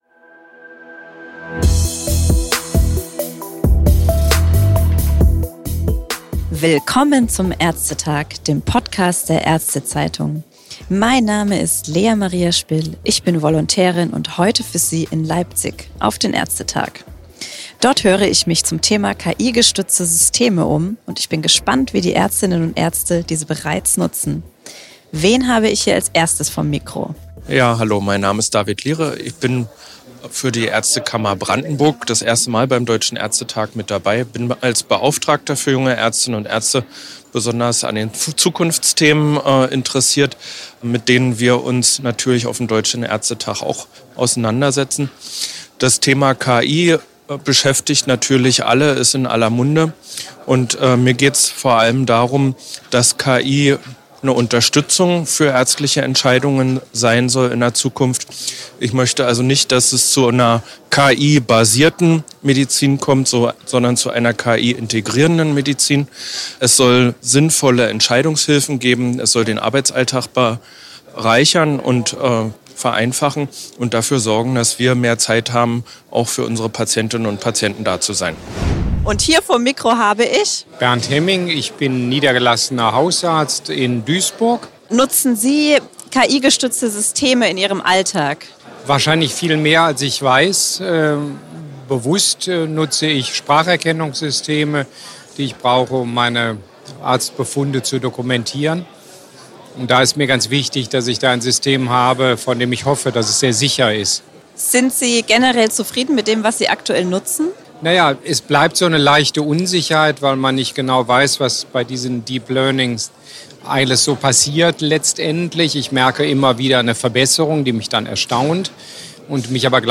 In unserer „ÄrzteTag vor Ort“-Podcast-Umfrage berichten drei Medizinerinnen und Mediziner über ihre Erfahrungen.